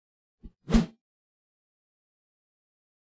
meleeattack-swoosh-heavy-group06-00.ogg